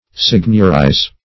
signiorize - definition of signiorize - synonyms, pronunciation, spelling from Free Dictionary
[1913 Webster] The Collaborative International Dictionary of English v.0.48: Signiorize \Sign"ior*ize\ (s[=e]n"y[~e]r*[imac]z), v. i. To exercise dominion; to seigniorize.